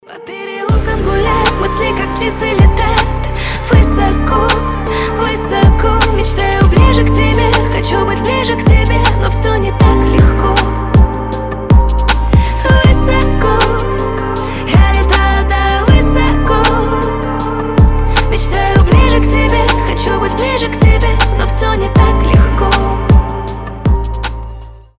женский голос